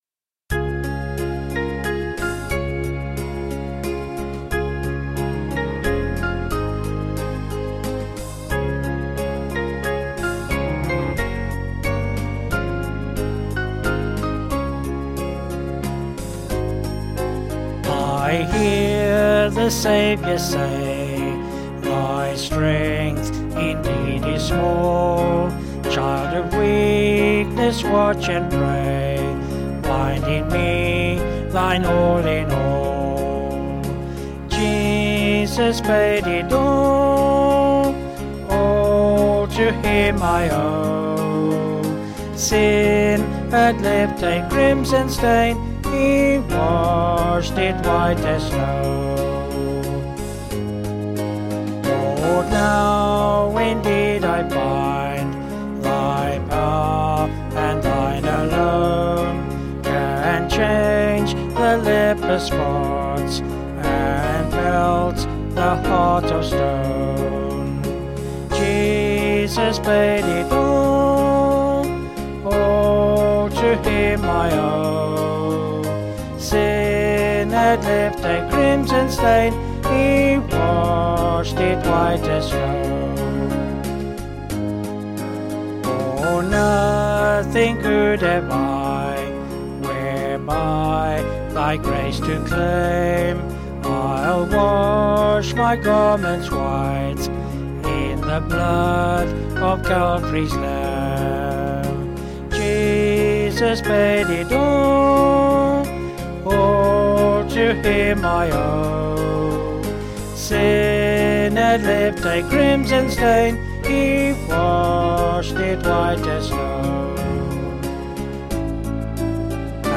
Vocals and Band   264.5kb Sung Lyrics